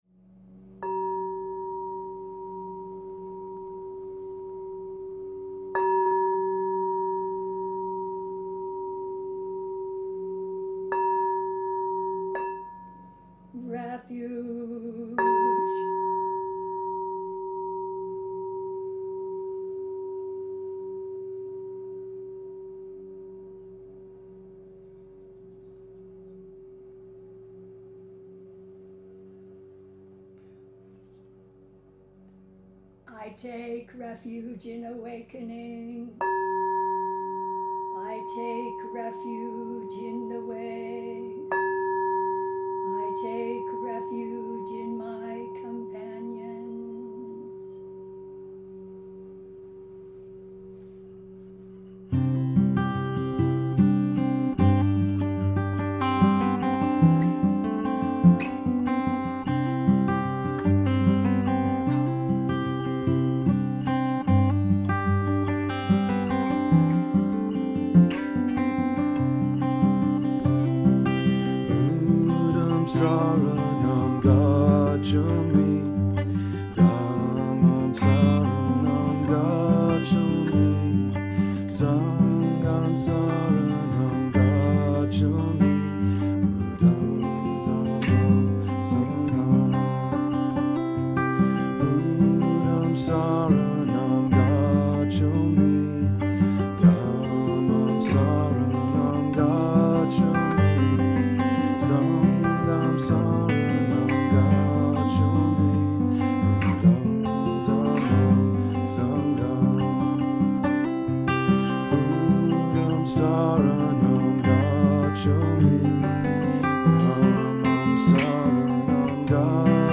Audio: Sutra Service in the Blue Dragon Cave’s
SutraServiceSatBlueDragon.mp3